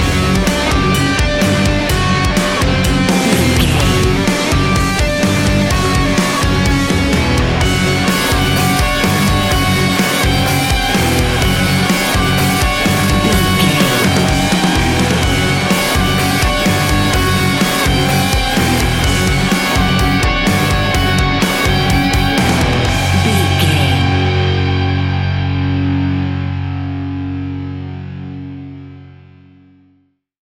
Ionian/Major
F♯
hard rock
guitars
heavy metal
instrumentals